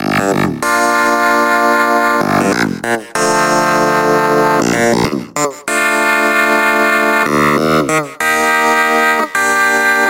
Tag: 95 bpm Weird Loops Synth Loops 1.70 MB wav Key : G